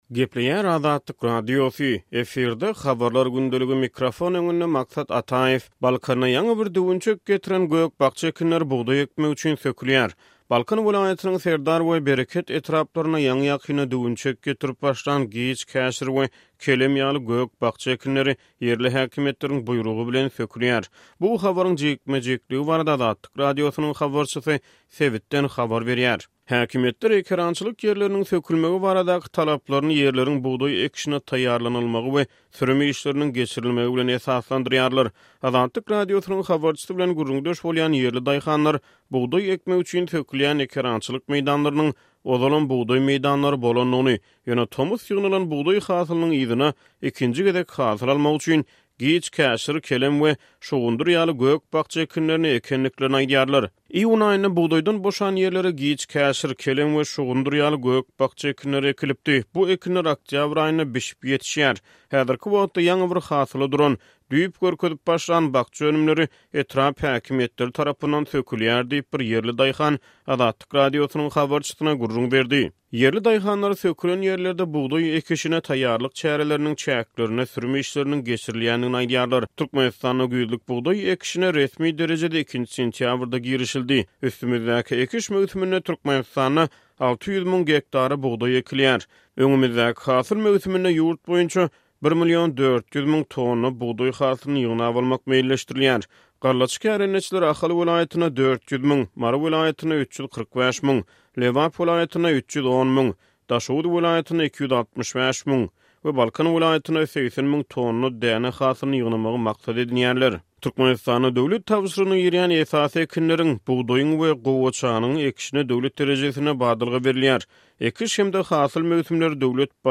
Balkan welaýatynyň Serdar we Bereket etraplaryndaky ekerançylyk meýdanlarynda ýaňy-ýakynda düwünçek getirip başlan giç käşir we kelem ýaly gök-bakja ekinleri ýerli häkimiýetleriň buýrugy bilen sökülýär. Bu habaryň jikme-jikligi barada Azatlyk Radiosynyň habarçysy sebitden habar berýär.